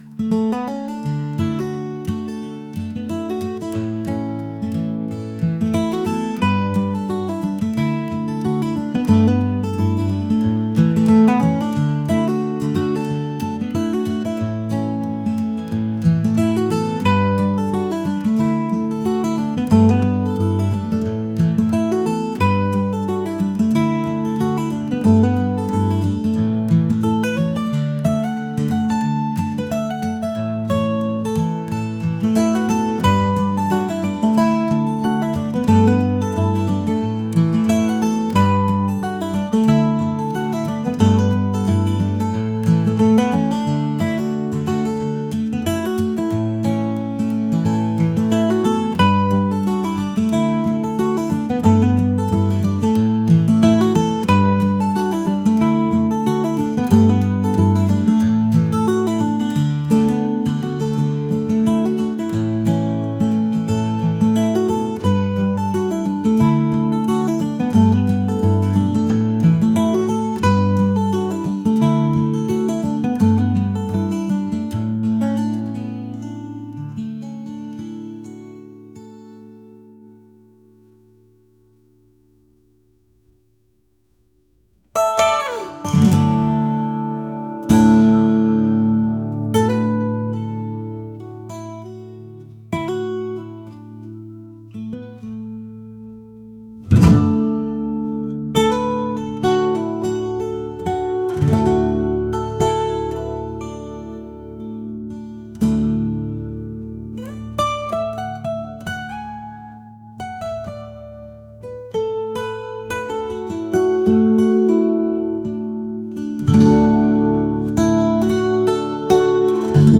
acoustic | folk | country